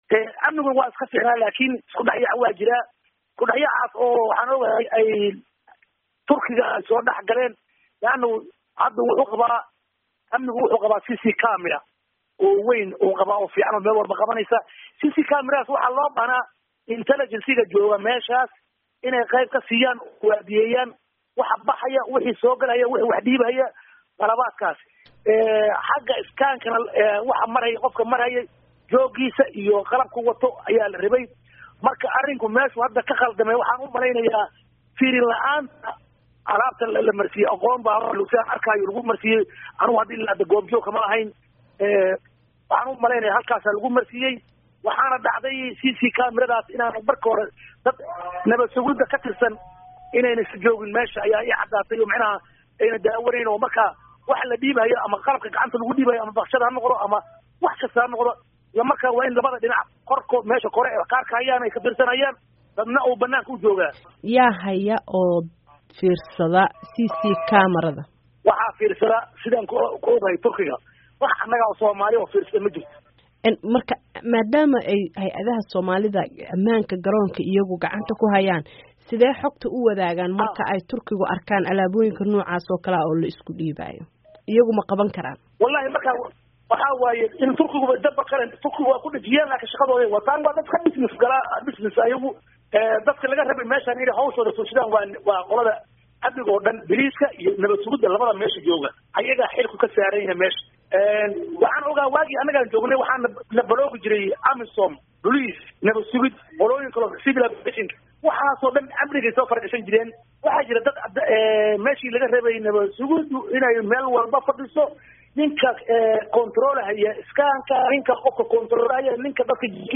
Wareysi: Ammaanka Garoonka Adan Cadde